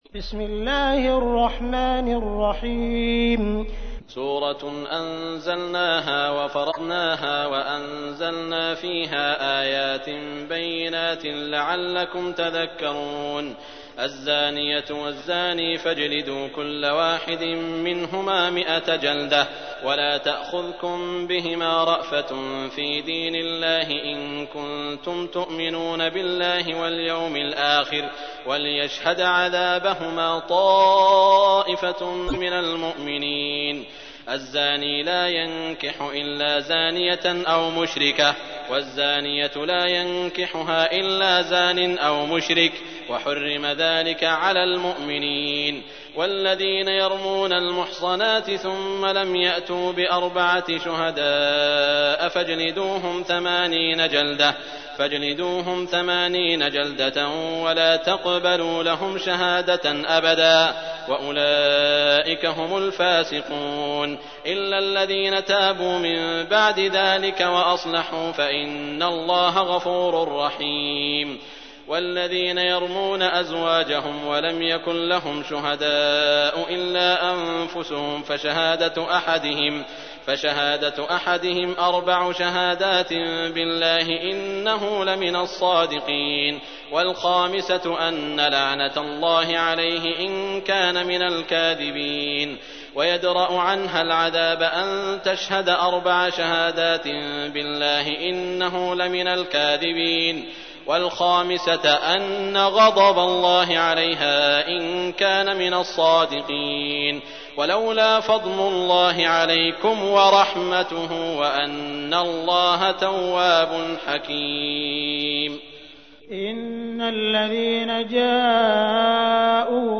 تحميل : 24. سورة النور / القارئ عبد الرحمن السديس / القرآن الكريم / موقع يا حسين